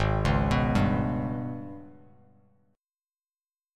G#mM7bb5 Chord